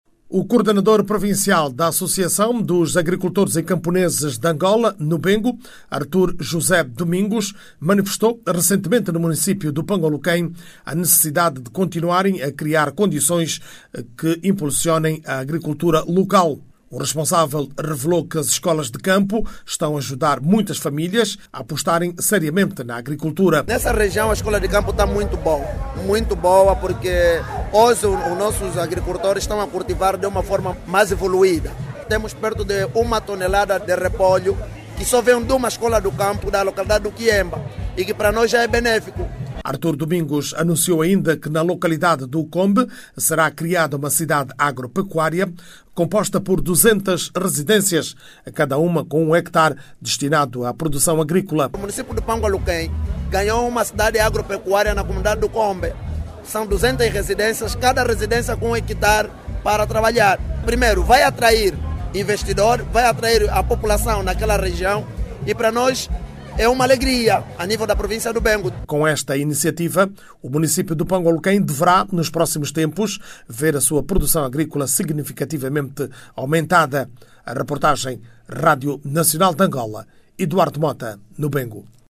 A província do Bengo, vai ganhar ainda este ano contar com uma cidade agropecuária, para potenciar a produção agrícola naquela região do país. A cidade agropecuária a ser construída no município de Pango Aluquém, deve ficar pronta até Dezembro deste ano e vai contar com Duzentas residenciais. Ouça no áudio abaixo toda informação com a reportagem